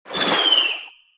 Sfx_creature_babypenguin_death_swim_03.ogg